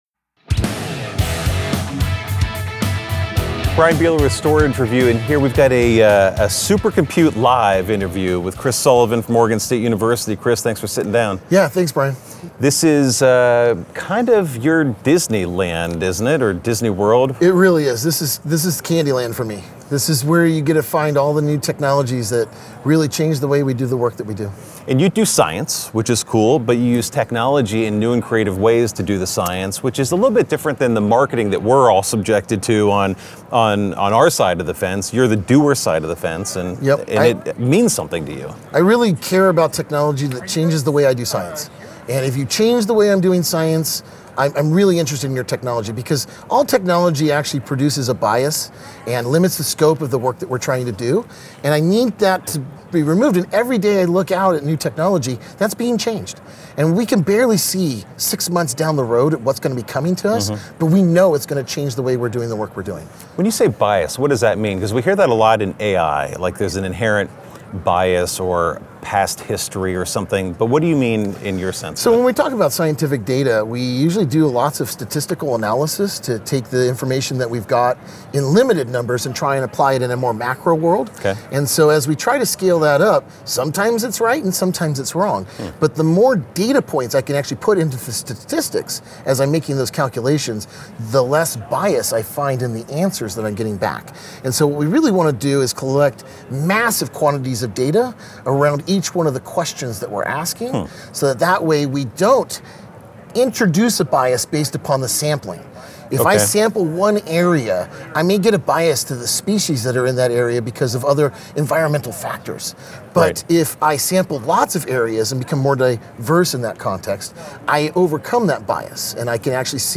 A live Supercomputing interview on edge AI, GPUs, and dense storage powering ocean science and real-time research at the edge.